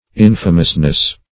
Search Result for " infamousness" : The Collaborative International Dictionary of English v.0.48: Infamousness \In"fa*mous*ness\, n. The state or quality of being infamous; infamy.